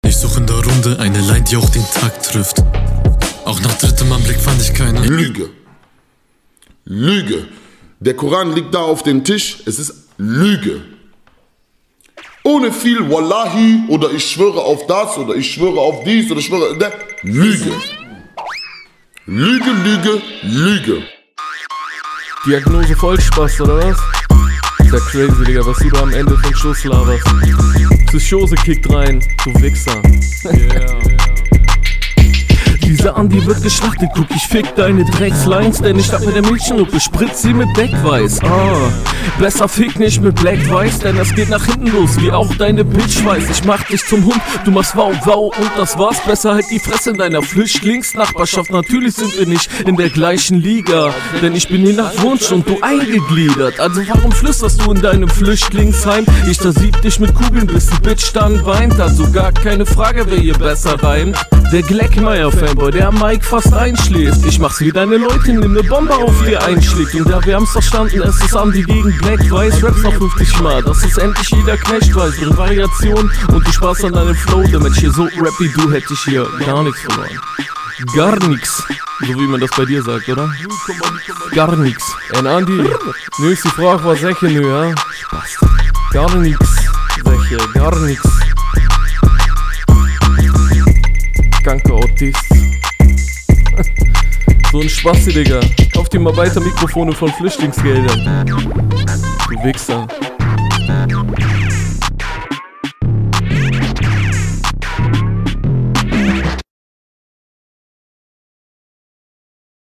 Geiler Beat
Der Beat ist echt schlimm